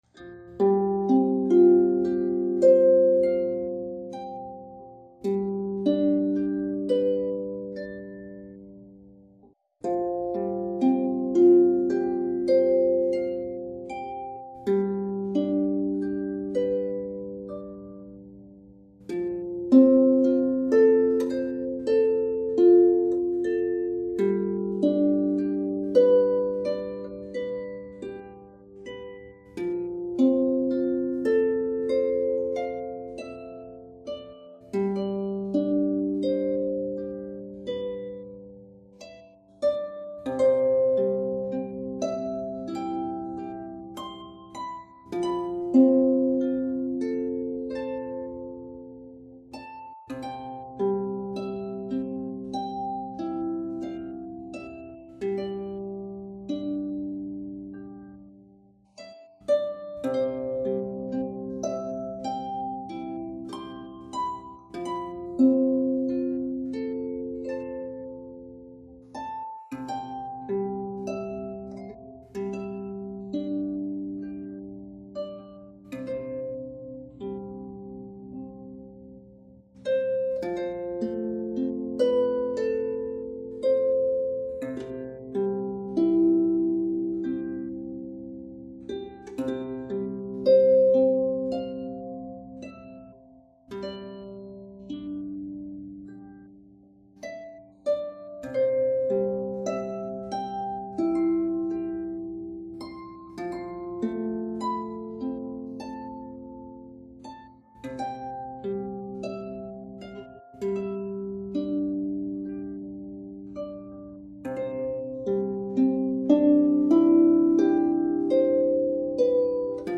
Guided imagery is a natural mind-body technique that combines music, imagery and simple relaxation techniques to ease stress and anxiety, enhance your well-being, and connect you to your feelings and emotions — all of which can promote the healing process.
Our audio program contains deep breathing and relaxation exercises to bring you to a level of peace and calm. The music and guided imagery bring you to a safe and tranquil place.